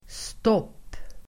Uttal: [ståp:]